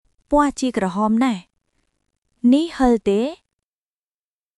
当記事で使用された音声（クメール語および日本語）は全てGoogle翻訳　および　Microsoft TranslatorNative Speech Generation、©音読さんから引用しております。